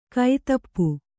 ♪ kai tappu